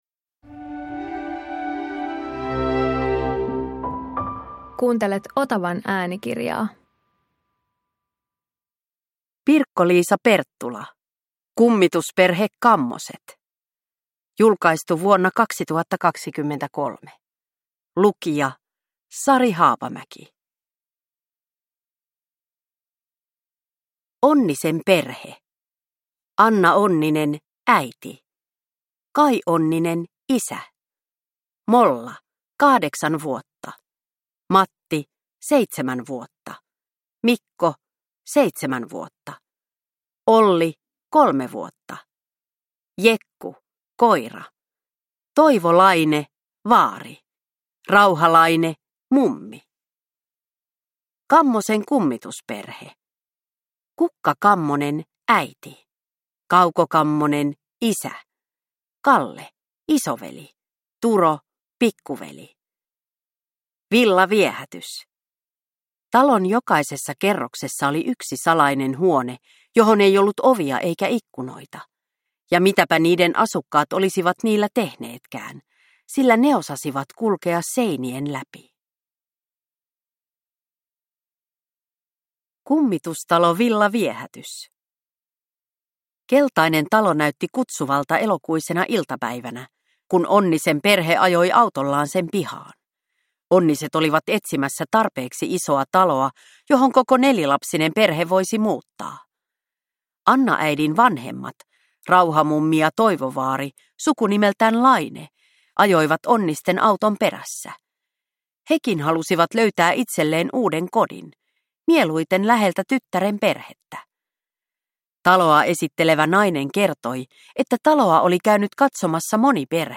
Kummitusperhe Kammoset – Ljudbok – Laddas ner